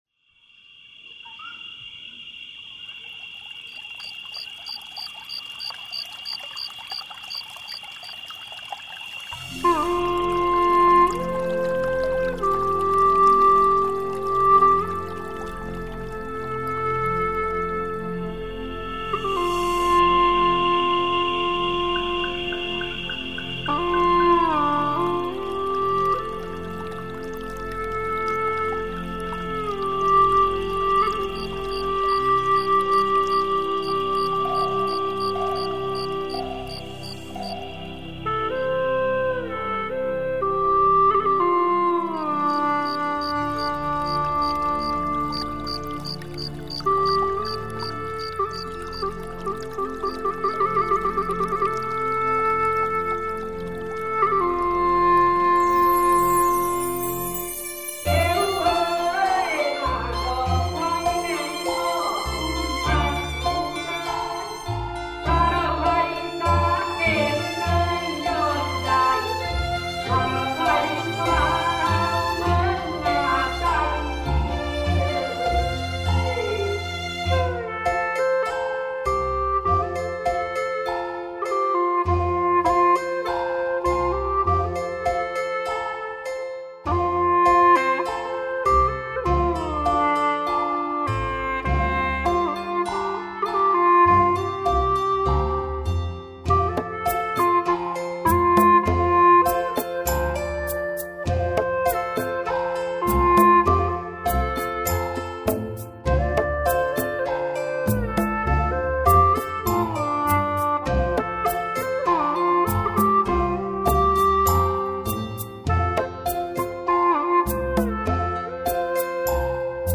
调式 : F调 曲类 : 独奏